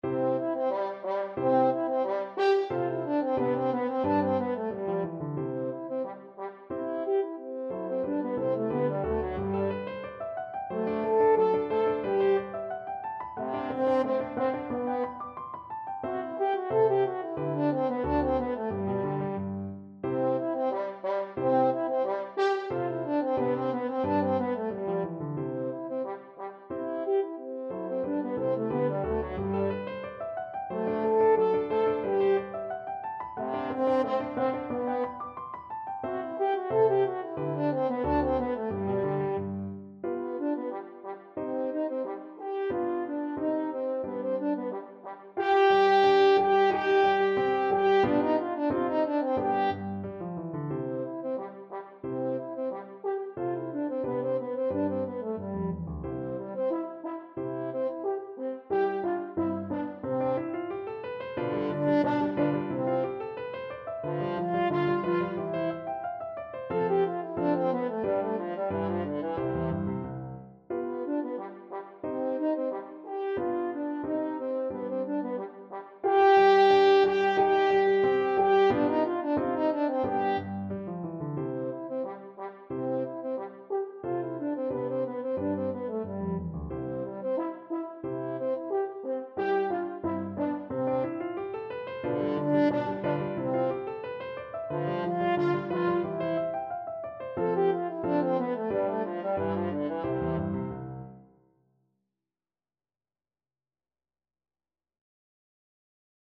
French Horn
C major (Sounding Pitch) G major (French Horn in F) (View more C major Music for French Horn )
Spiritoso Spiritoso = 180
2/2 (View more 2/2 Music)
Classical (View more Classical French Horn Music)